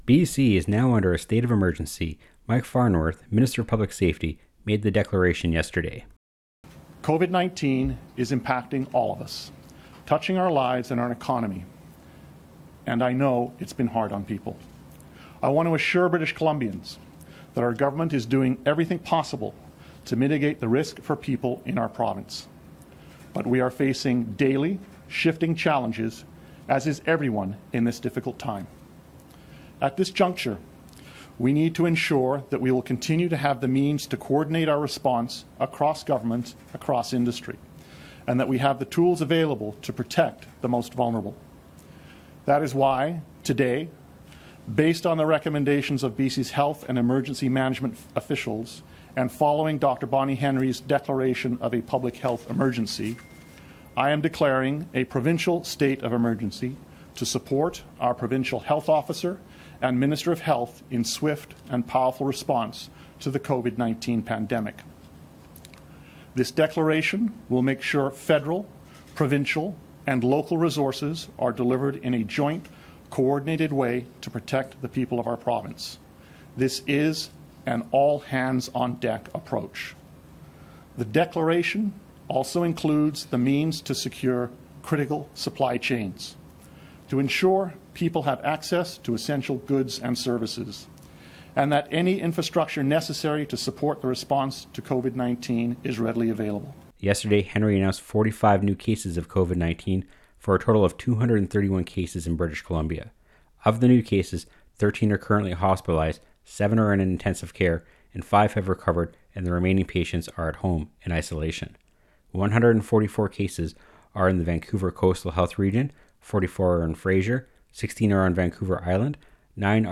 Featured Speakers/Guests: Includes clips from Mike Farnworth, Minister of Public Safety and BC Provincial Health officer Bonnie Henry.
Type: News Reports
256kbps Stereo